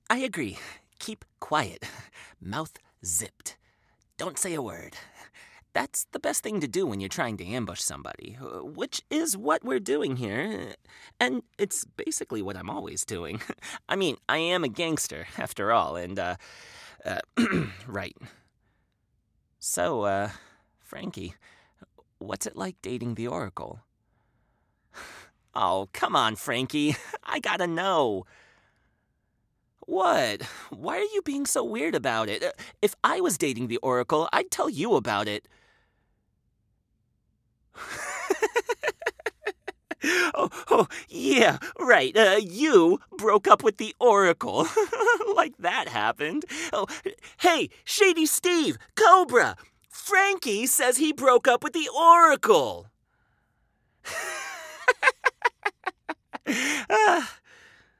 Inglés (Estados Unidos)
Animación
Adulto joven
Mediana edad